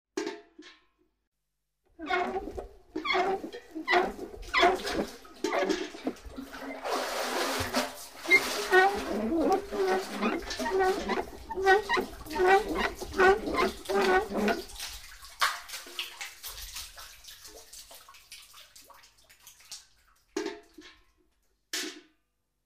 Звуки деревни
Набираем воду в колодце для скота